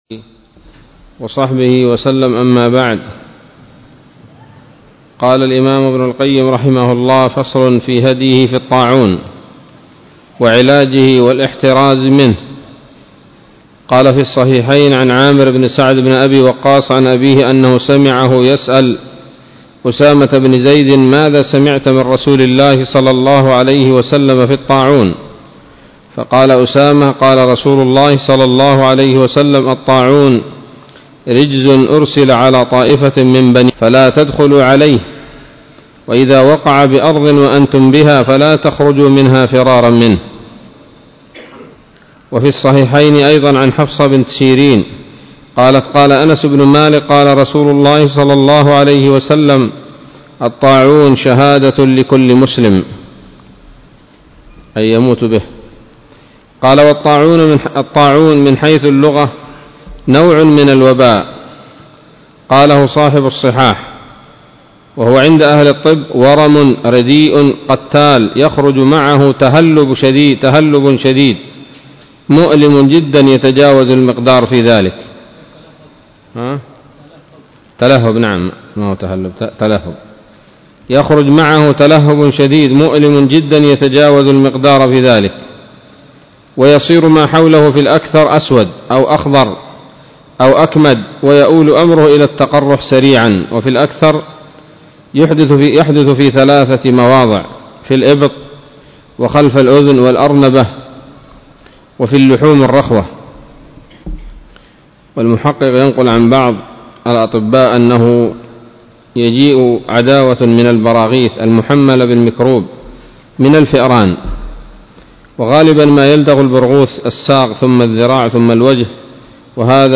الدرس العاشر من كتاب الطب النبوي لابن القيم